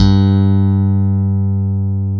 Index of /90_sSampleCDs/Roland LCDP02 Guitar and Bass/BS _Stik & Dan-O/BS _Chapmn Stick